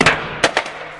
skateboards » bounceback
描述：skateboard bounce grind
标签： bounce grind skateboard
声道立体声